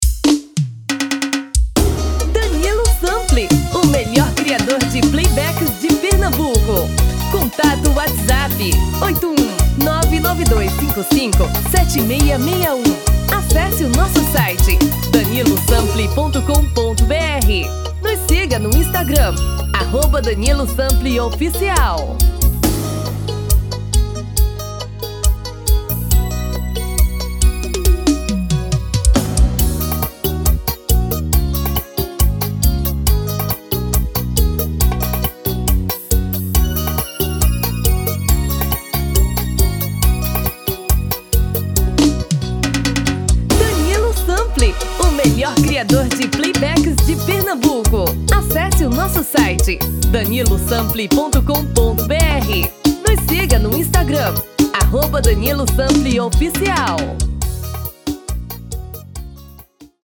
DEMO 1: tom original | DEMO 2: tom feminino